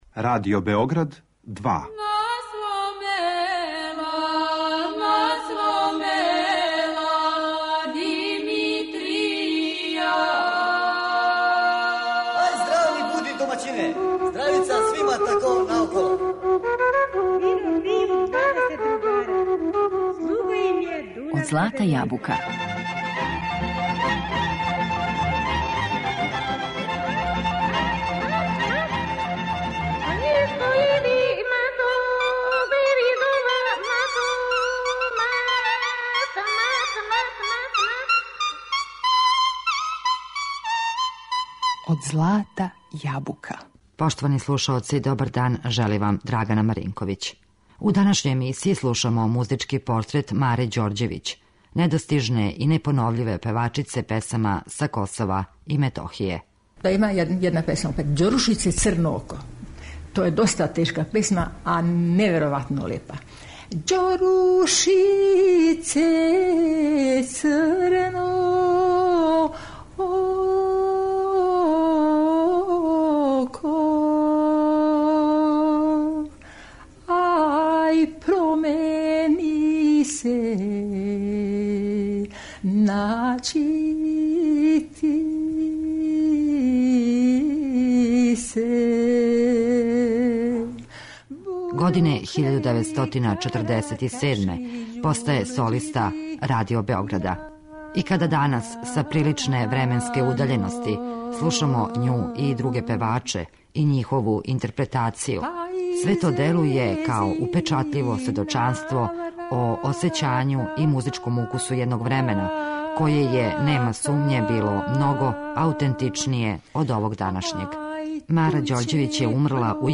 Музички портрет Маре Ђорђевић
Данашњу емисију посветили смо Мари Ђорђевић, недостижној и непоновљивој уметници, најаутентичнијем тумачу изворне косовске народне песме.